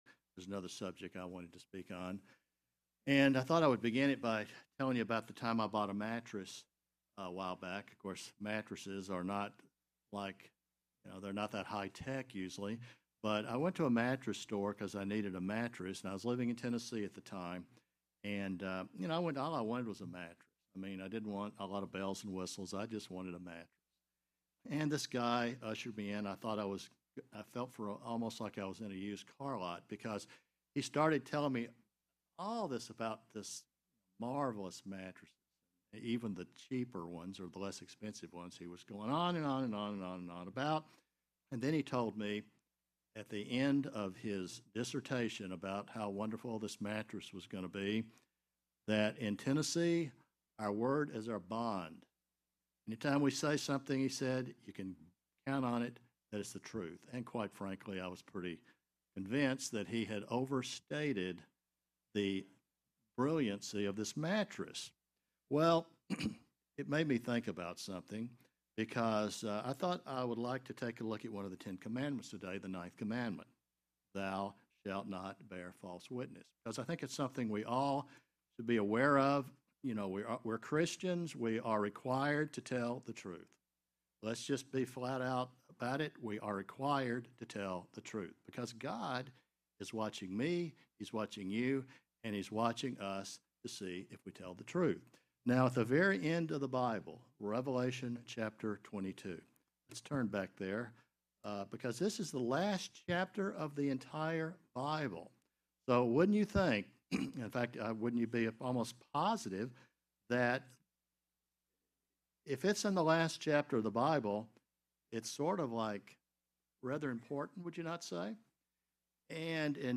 Given in Tampa, FL